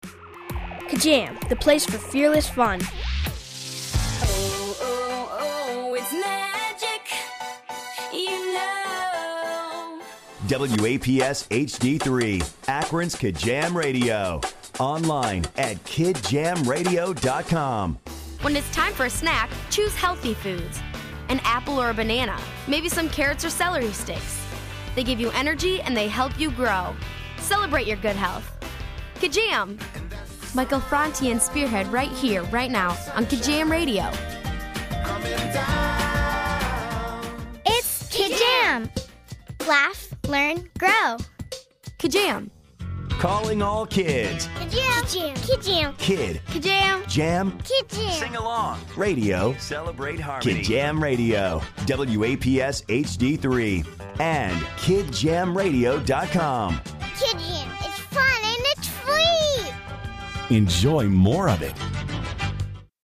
A radio station created to empower and entertain children while enhancing self-motivation, good nutrition, and creating high self esteem with appropriate music styles and messages in between songs